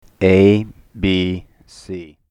fade out in which the volume diminishes at the end:
abcfadeout128.mp3